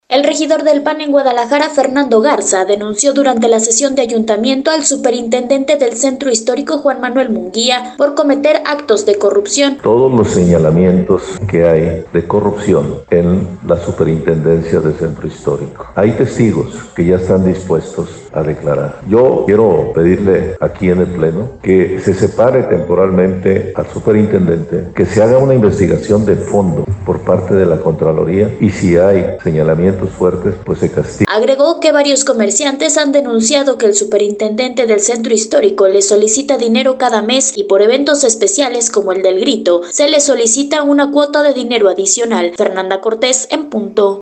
El regidor del PAN en Guadalajara, Fernando Garza denunció durante la sesión de ayuntamiento al superintendente del Centro Histórico, Juan Manuel Munguía de cometer actos de corrupción.